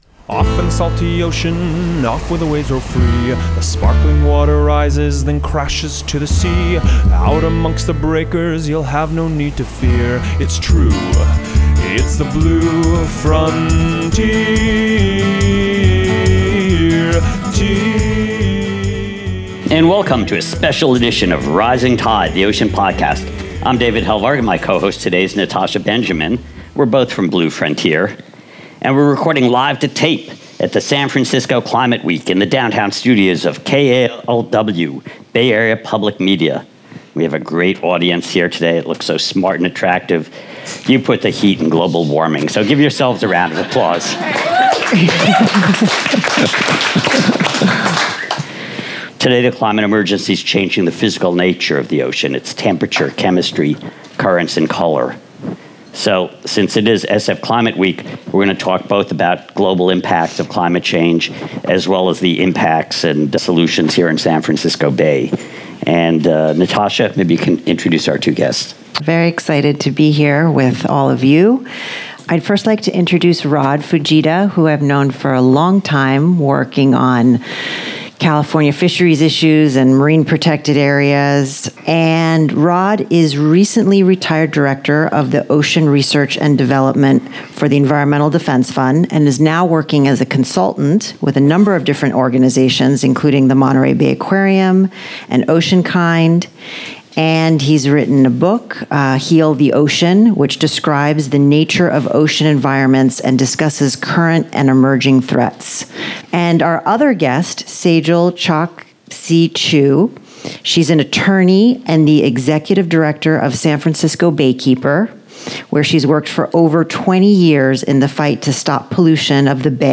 On Friday April 25 the Rising Tide Ocean Podcast recorded our show with a live audience during SF Climate Week – one of the largest climate gatherings in the world.